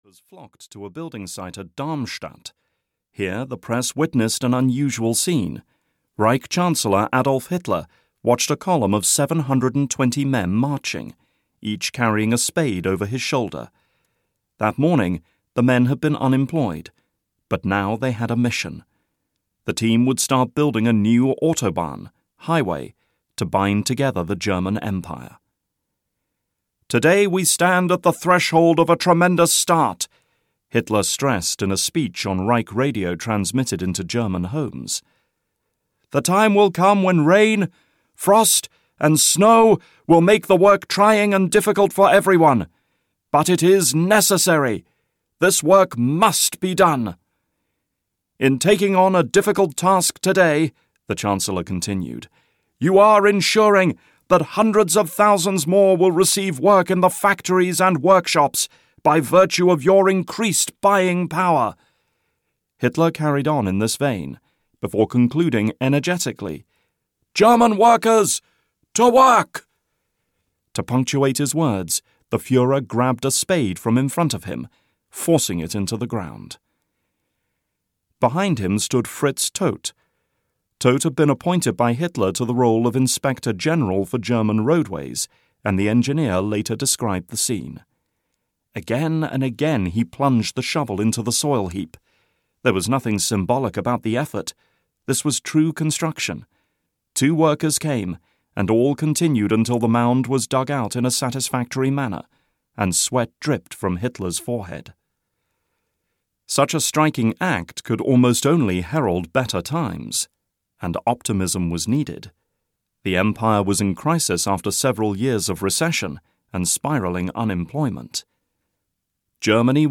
Inside the Third Reich (EN) audiokniha
Ukázka z knihy